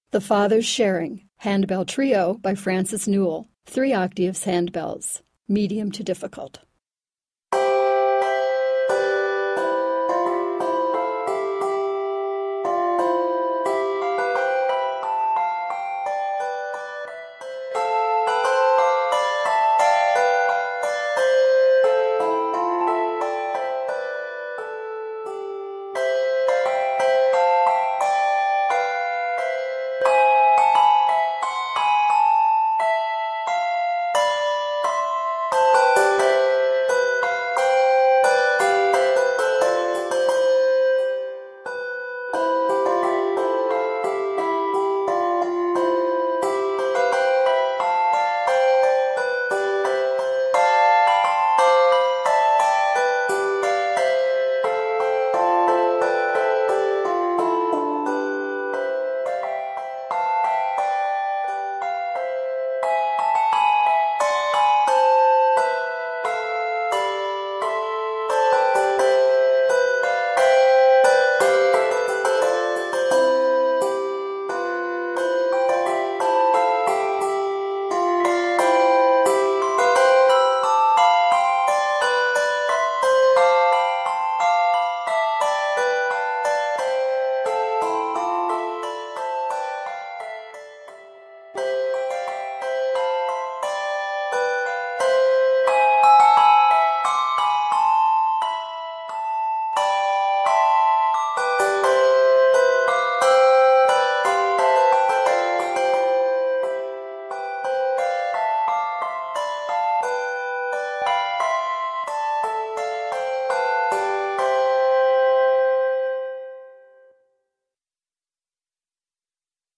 The music is both bright and appealing.
All Year Less than a full handbell choir: Trio